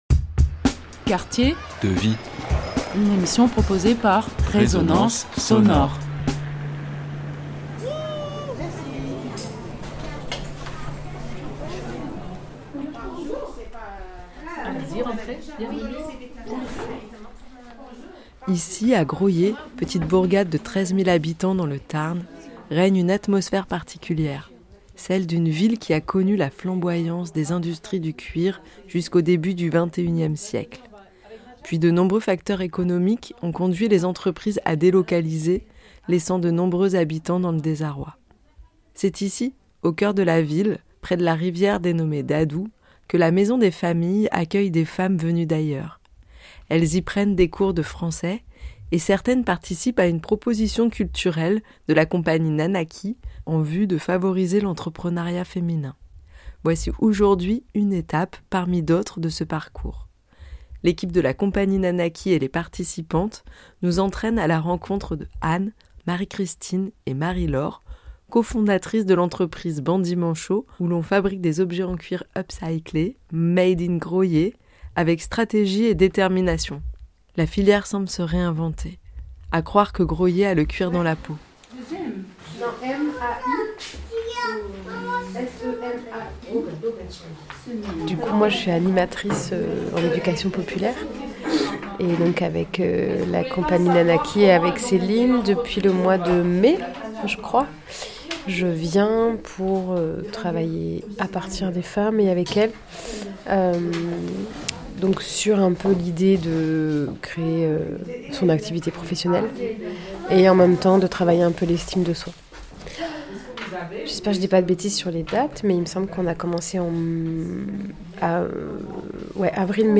Comme chaque semaine, les participantes du groupe de FLE, français langue étrangère, se retrouvent à la Maison des familles de Graulhet.